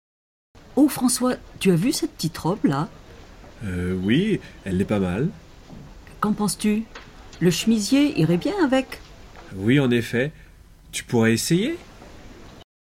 Petits dialogues